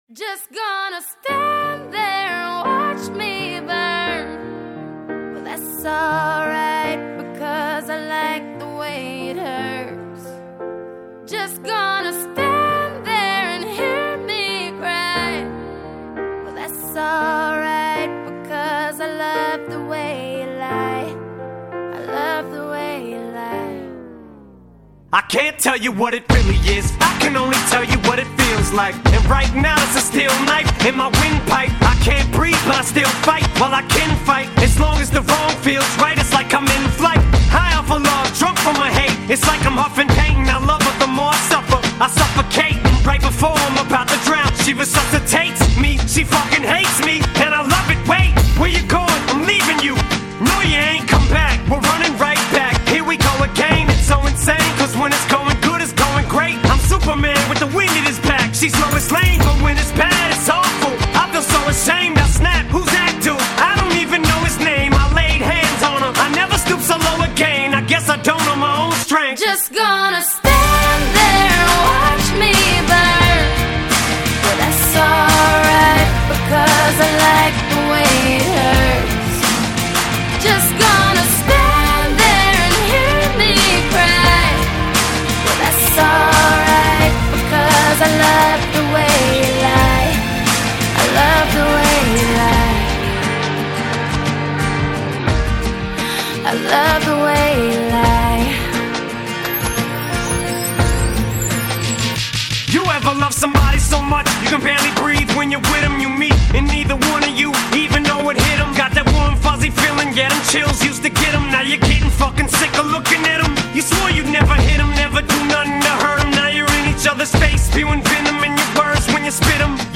Жанр: foreignrap